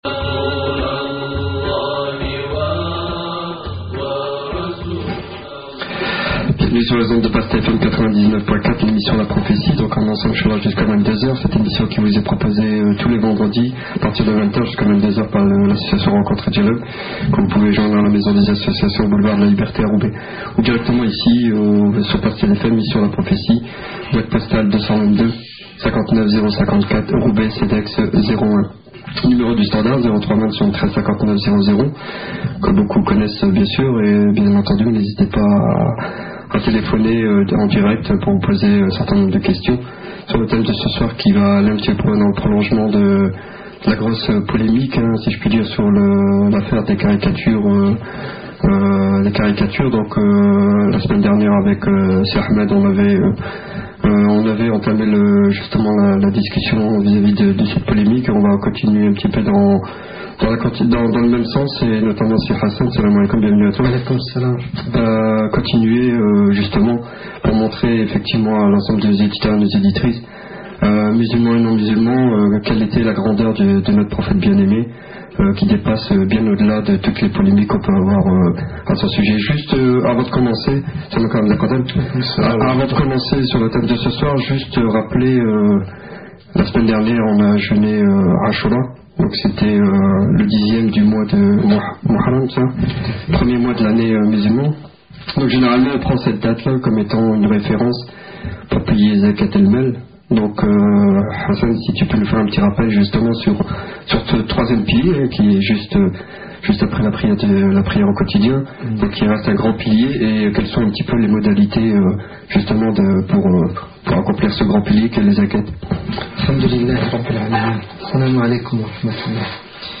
PASTEL FM l'émission la prophétie du vendredi 17 février 2006 pastelfm Conférence de